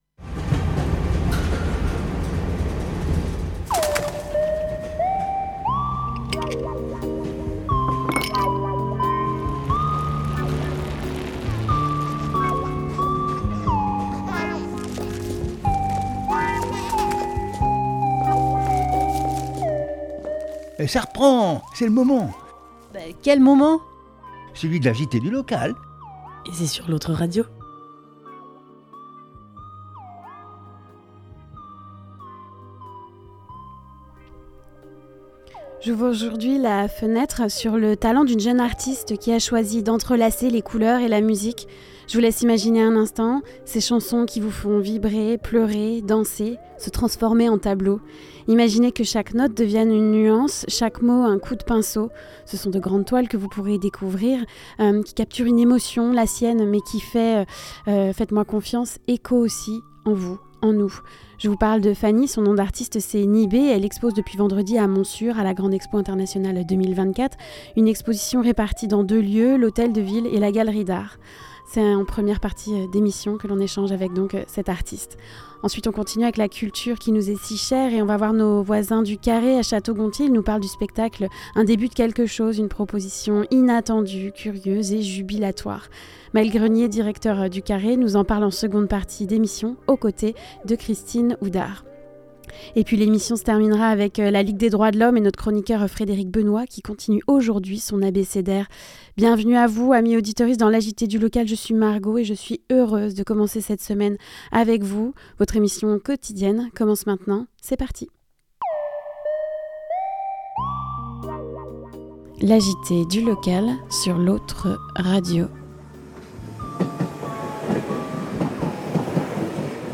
La chronique de La Ligue des Droits de l'Homme